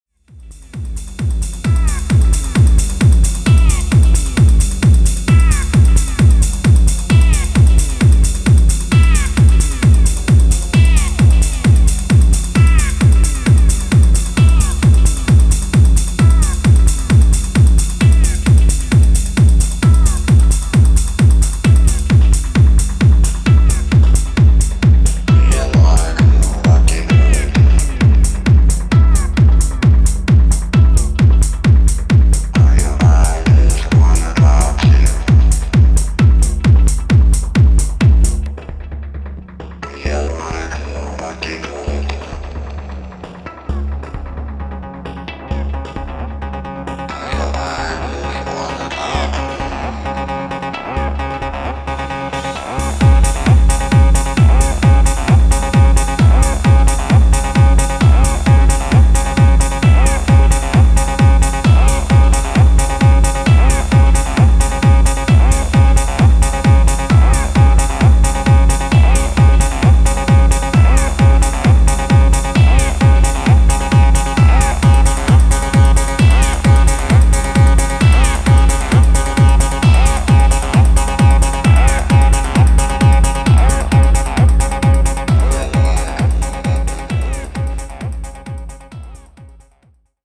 Techno Acid